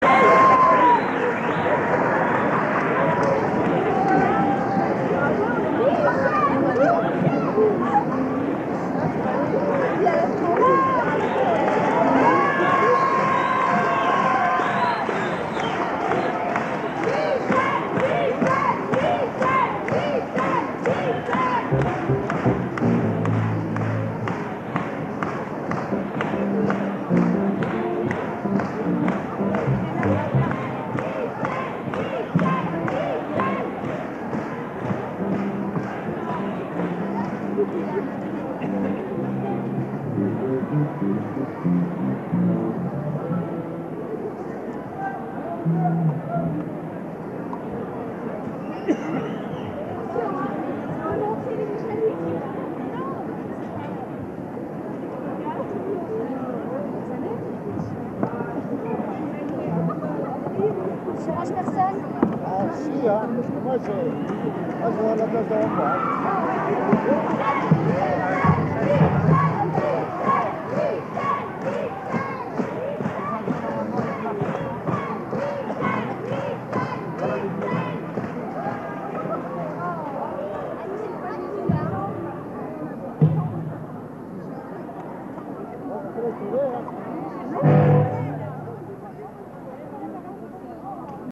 Bootlegs (enregistrements en salle)
Nancy (1 avril 1980)